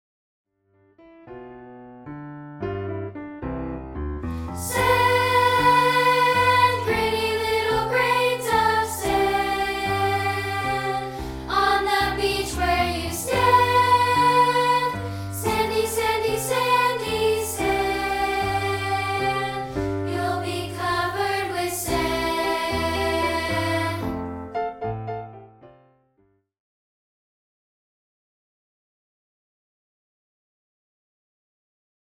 No. 5 and made it available as a free rehearsal track.